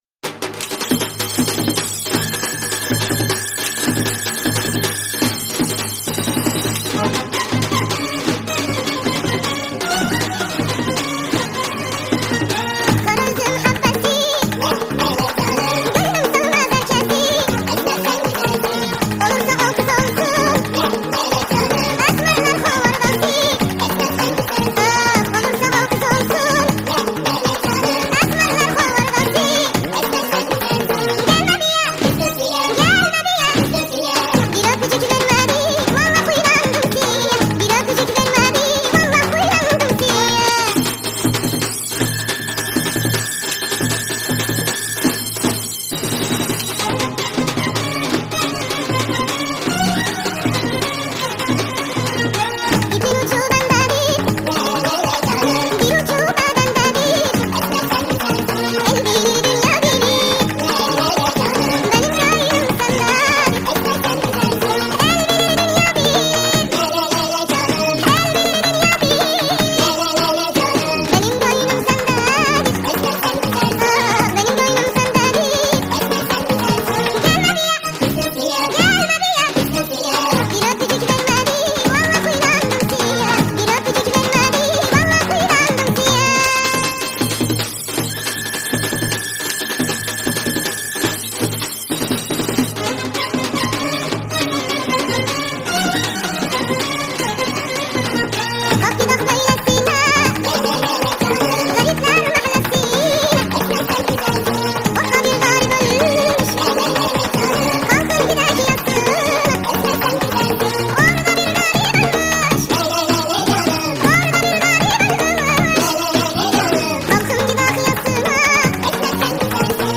با صدای بچه